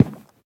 Minecraft Version Minecraft Version snapshot Latest Release | Latest Snapshot snapshot / assets / minecraft / sounds / block / cherry_wood / step3.ogg Compare With Compare With Latest Release | Latest Snapshot
step3.ogg